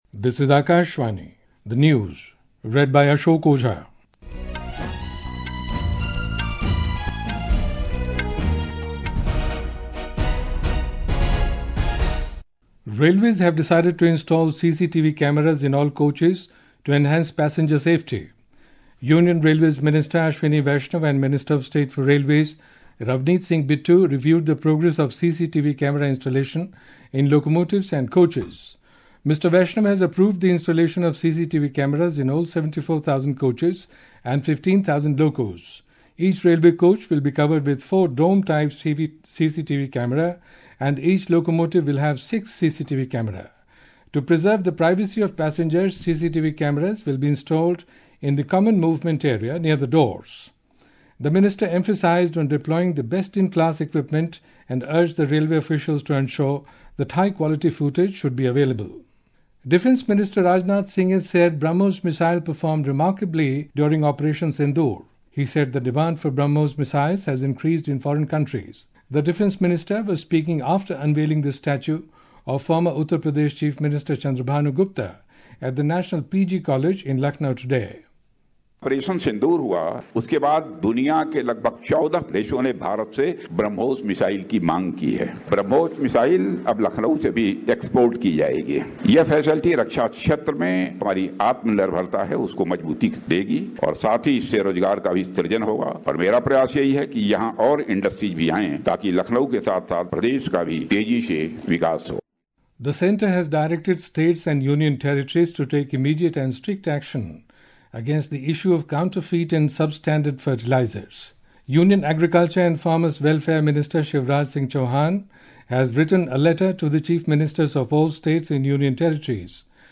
This audio news bulletin titled Hourly News in the category Hourly News .